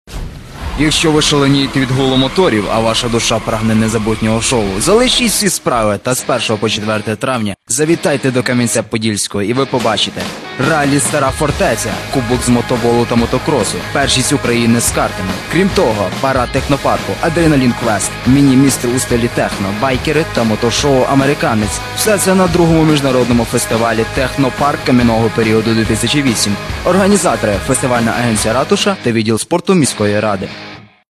Трохи якість погана вийшла....